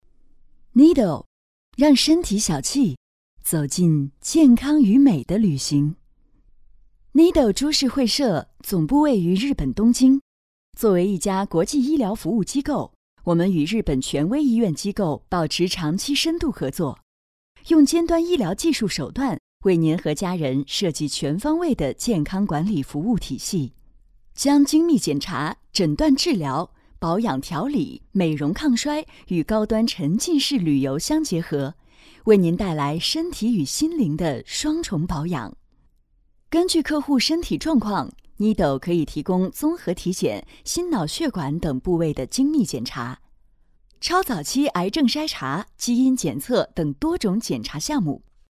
产品解说女52号（时尚质感
年轻时尚 产品解说
磁性质感双语（英文）女声，擅长旁白、宣传片不同题材，可模仿台湾腔。